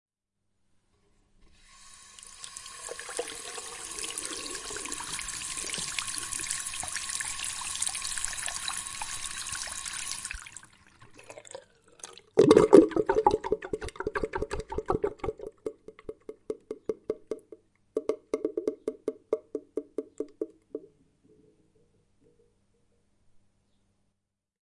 排水潺潺
描述：浴室水槽的水顺着下水道流下，发出咕噜咕噜的声音，用佳能数码摄像机GL2用DAK超指向性枪型麦克风，型号UEM83R
标签： 浴室 漏极 汩汩 水槽
声道立体声